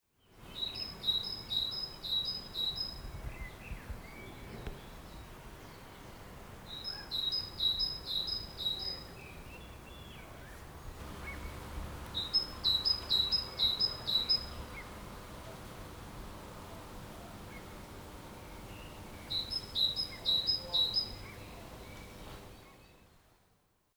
ti-ti-tyi) kuuluu nykyisin harvemmin kuin sen kaksitavuiset versiot tit-tyy tai
0506talitiainen.mp3